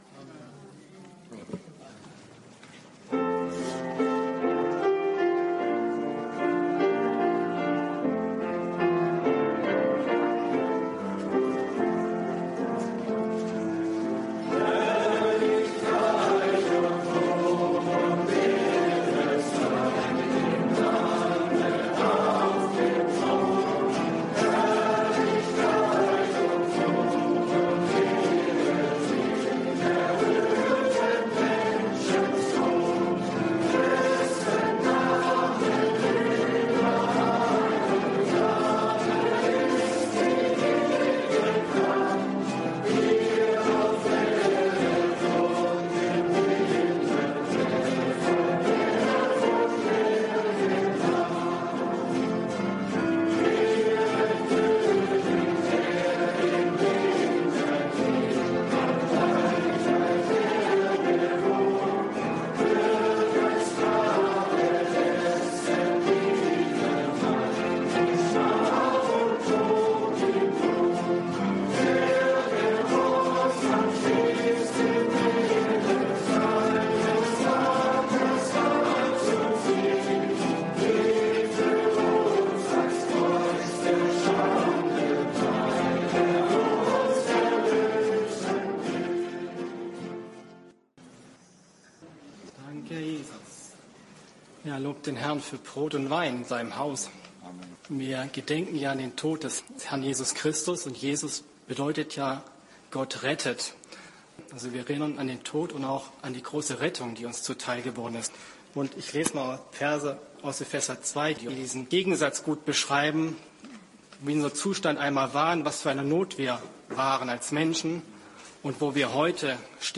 Auszug Gemeindeversammlung 22.3.2026